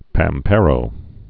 (păm-pârō, päm-)